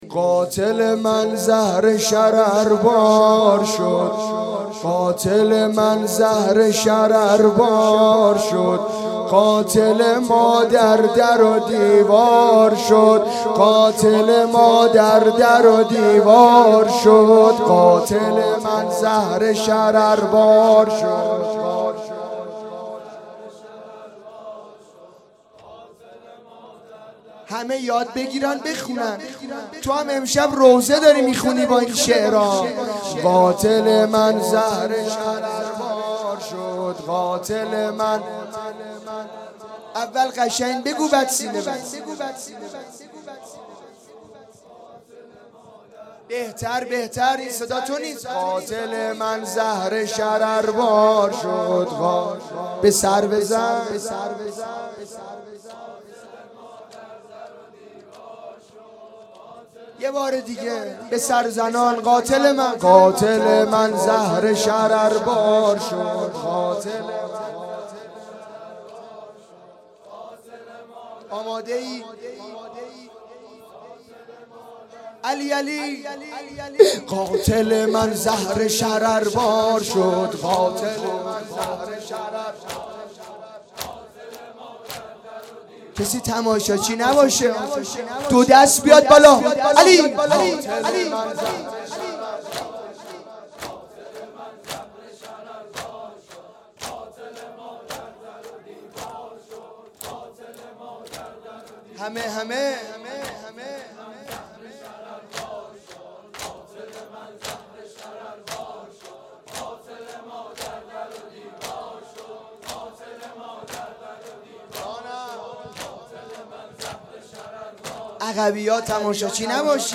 اقامه عزای رحلت پیامبر اکرم و شهادت امام حسن مجتبی علیه السلام